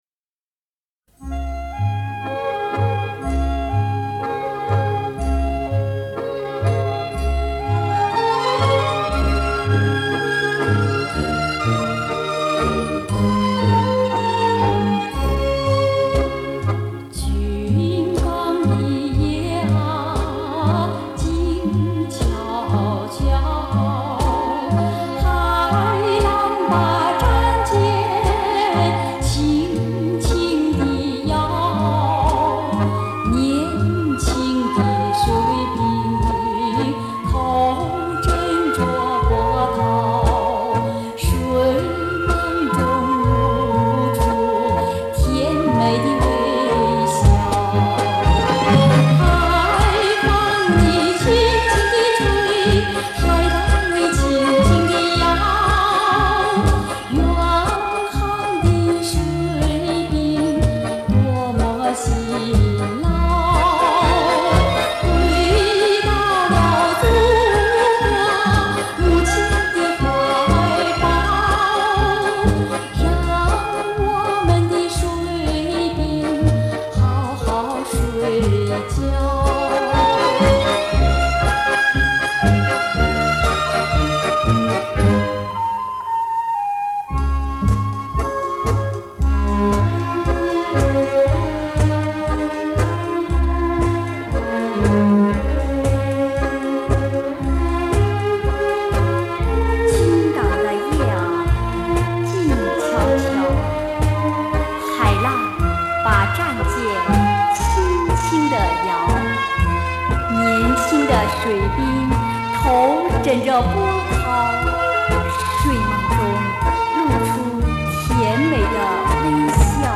换了张磁带重新制作上传，希望音质能让你满意